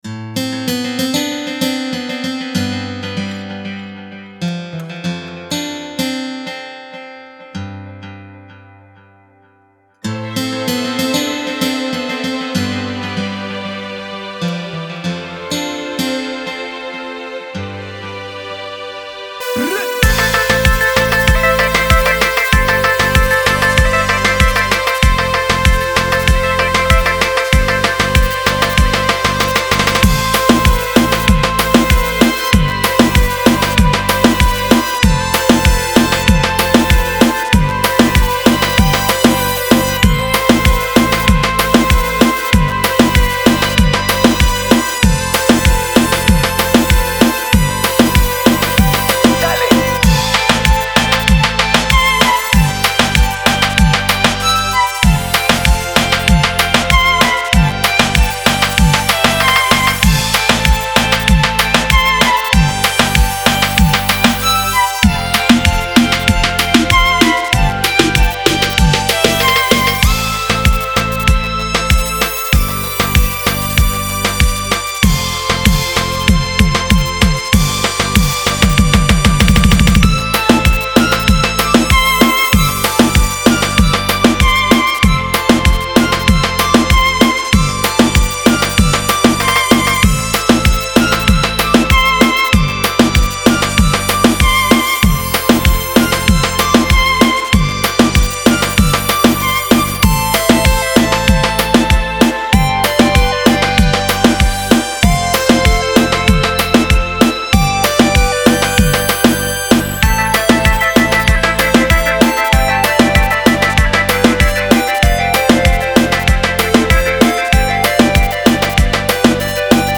Home > Music > Latin > Bright > Medium > Laid Back